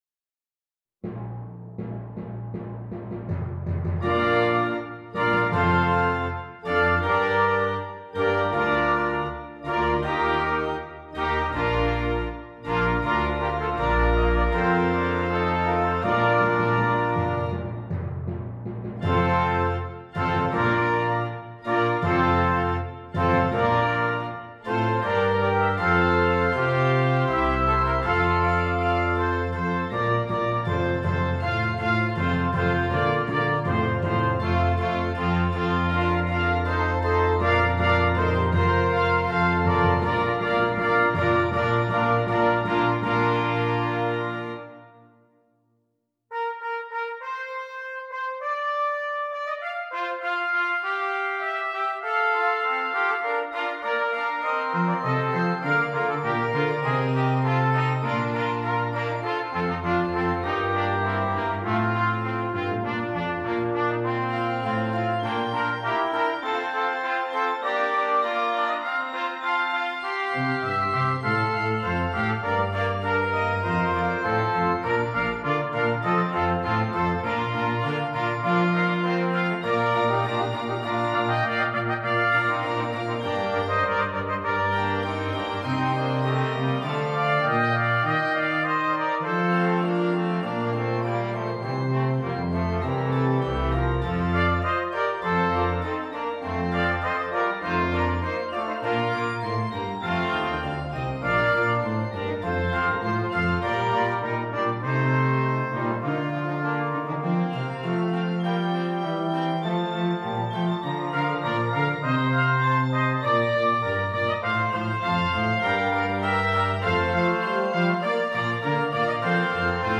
Brass Quintet and Organ and Timpani